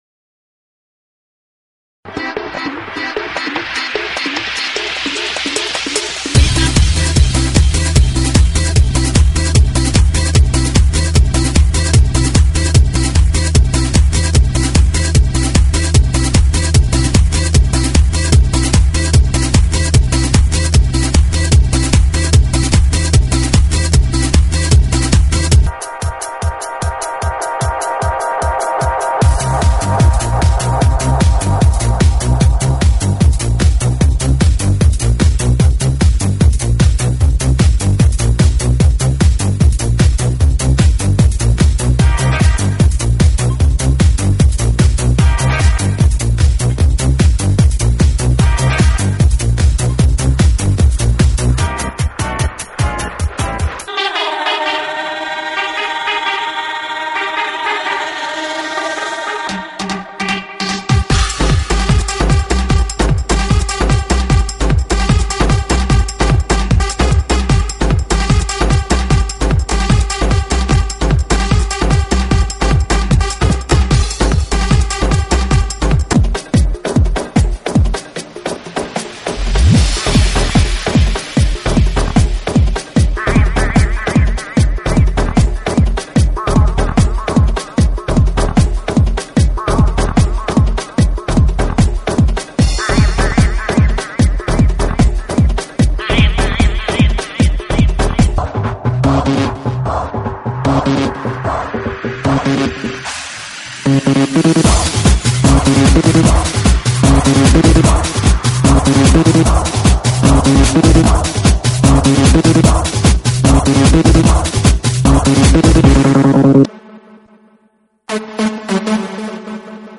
GENERO: ELECTRONICA – DANCE
ELECTRONICA, DANCE,